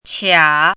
chinese-voice - 汉字语音库
qia3.mp3